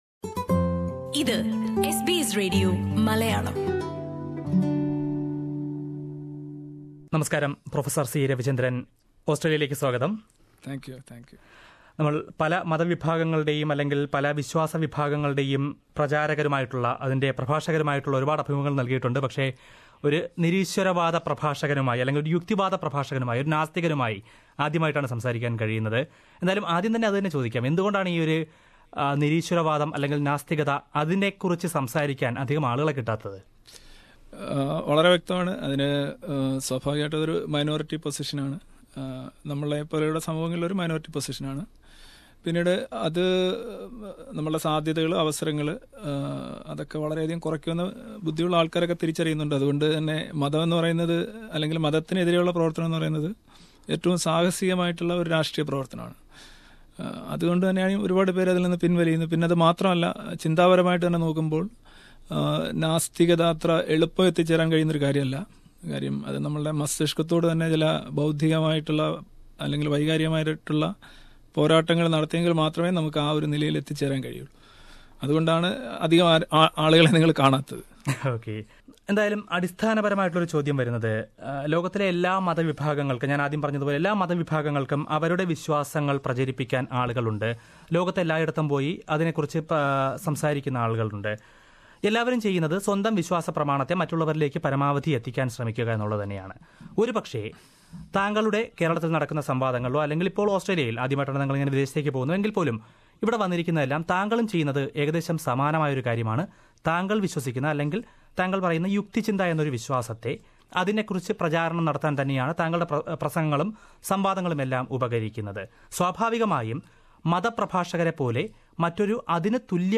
അഭിമുഖം